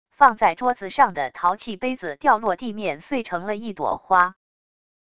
放在桌子上的陶器杯子掉落地面碎成了一朵花wav音频